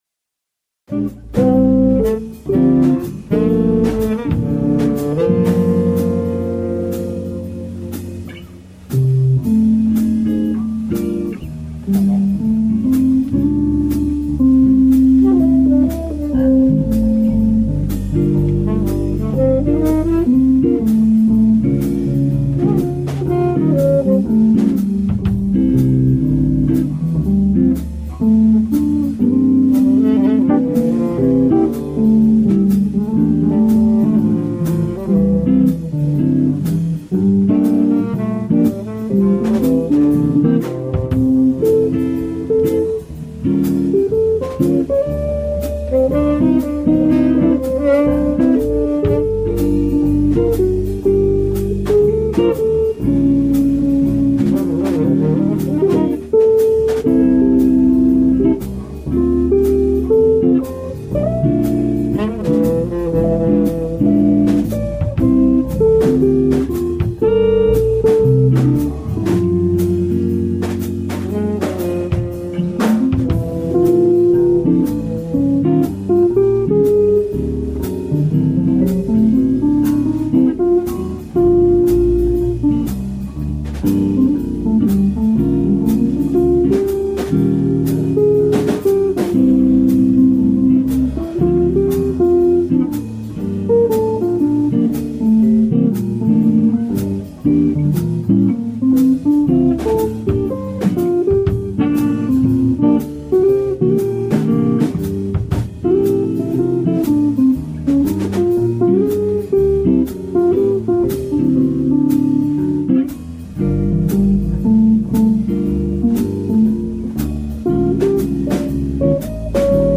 Style jazz